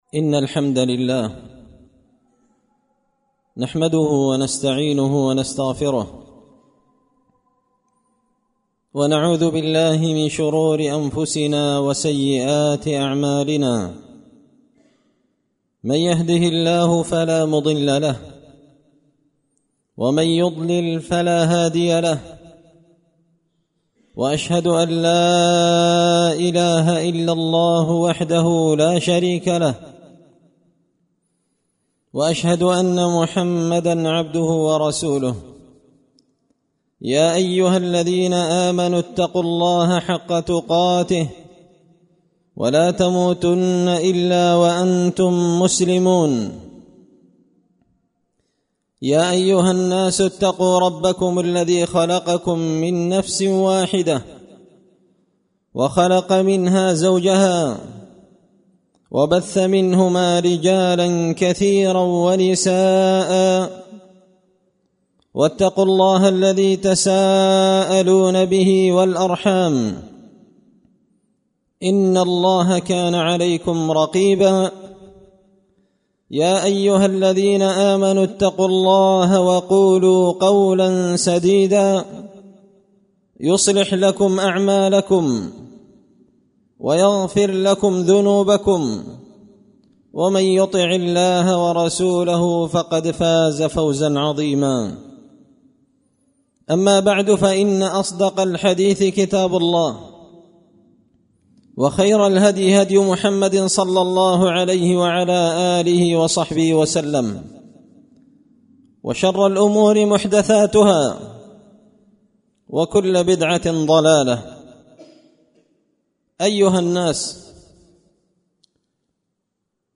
خطبة جمعة بعنوان – الحكم والعبر في الابتلاأت
دار الحديث بمسجد الفرقان ـ قشن ـ المهرة ـ اليمن